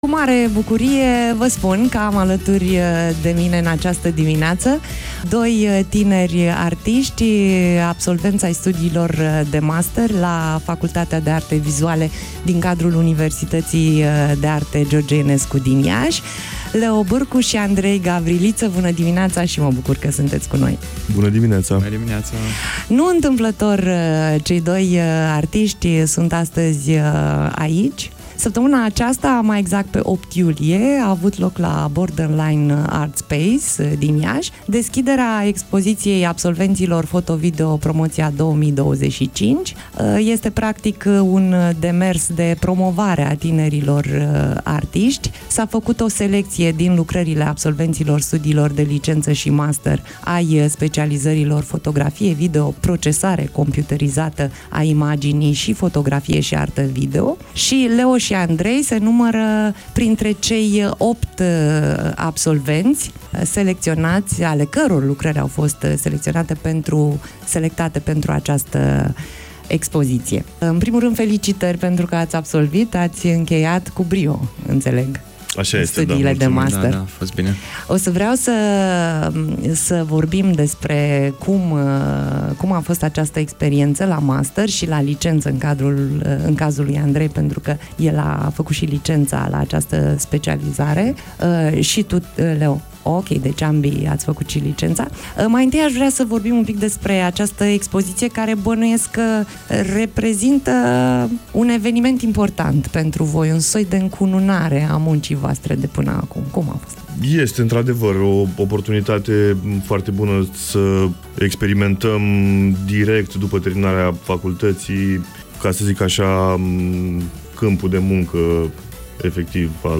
(INTERVIU)
interviu